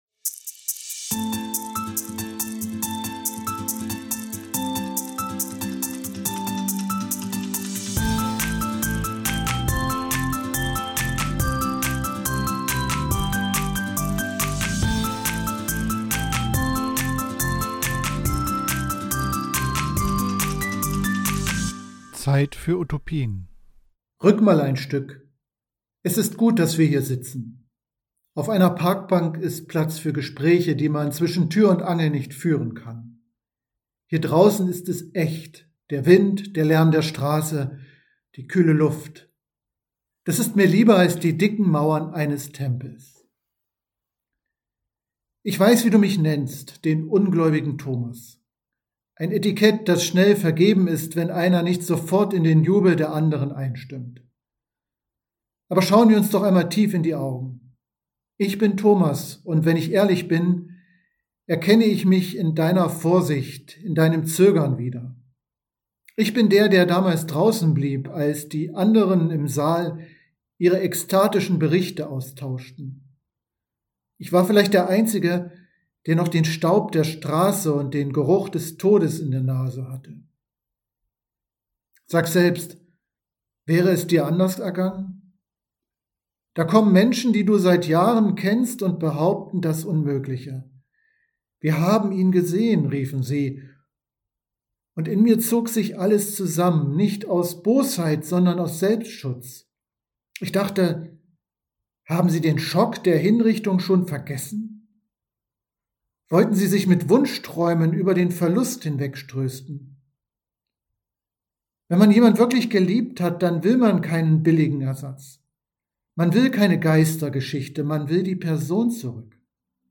Predigt am 12.4.2026 in der Kathedrale St. Sebastian